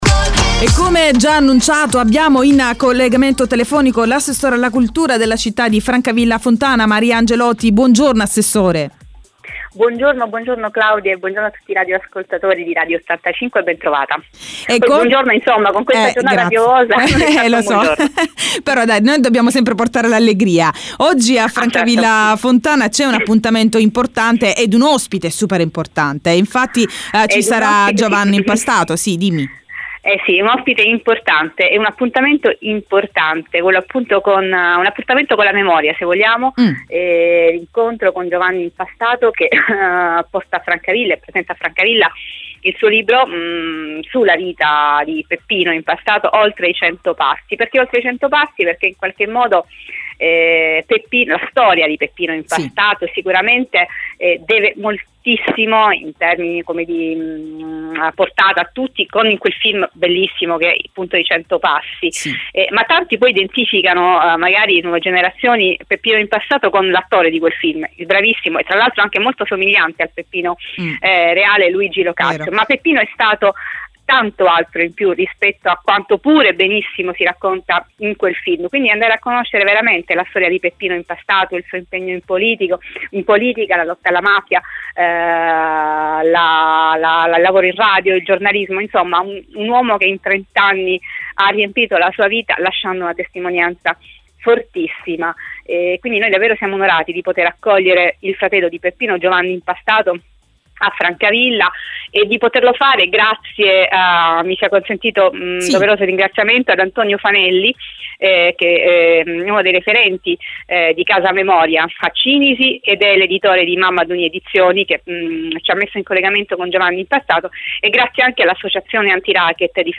Il Mattino di Radio85 – Presentazione del libro ” Oltre i Cento Passi ” – intervista all’assessore Maria Angelotti